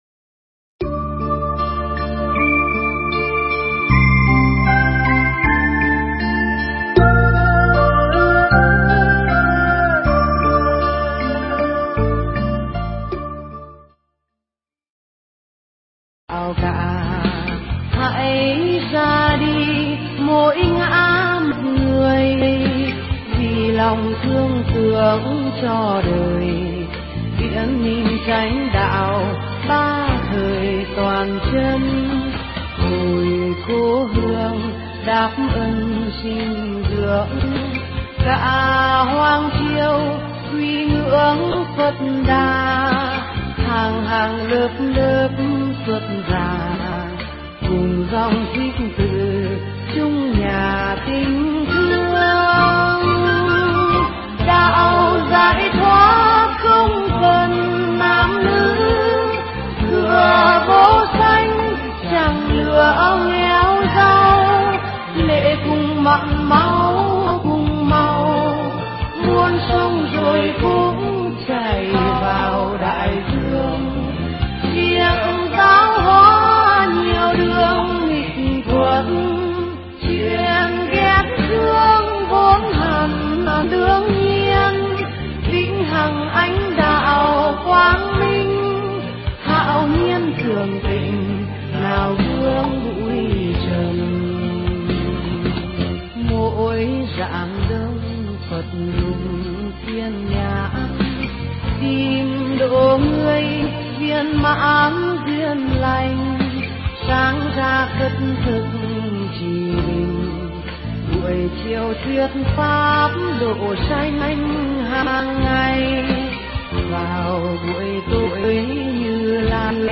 Nghe Mp3 thuyết pháp Niệm Tâm